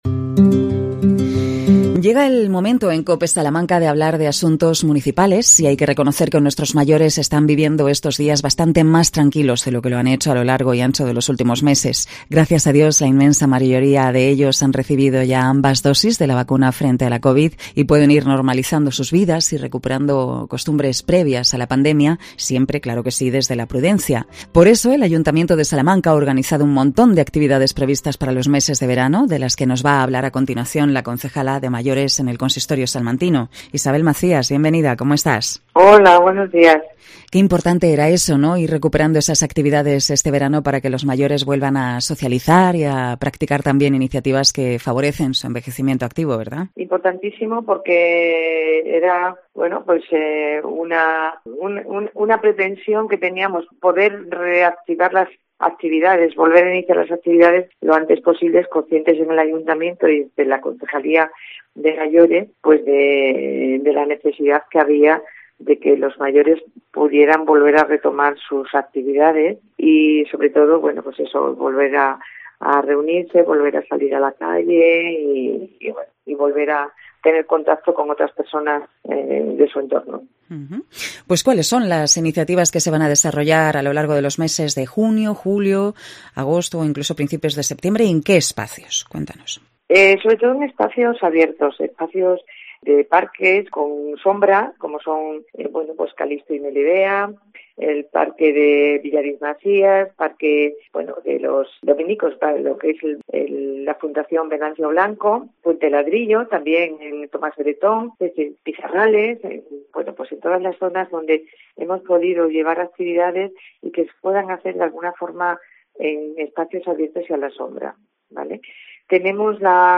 AUDIO: Cope Salamanca entrevista a la concejala de Mayores en el Ayto. Isabel Macías